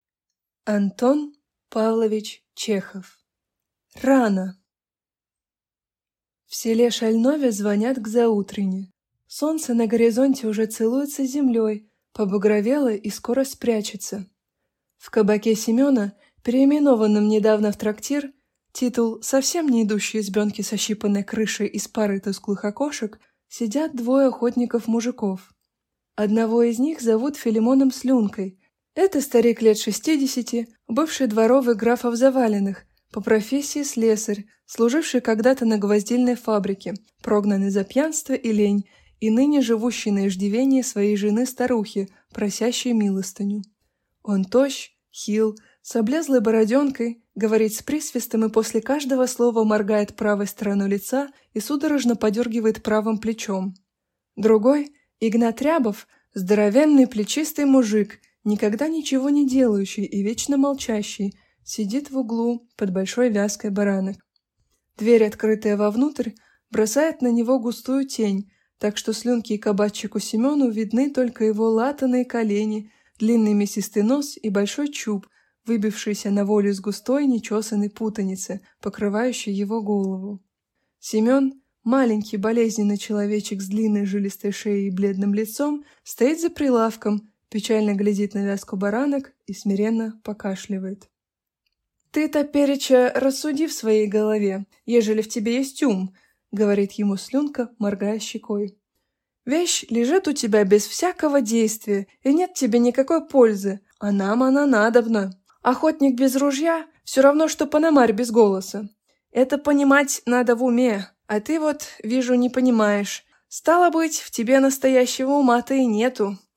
Аудиокнига Рано!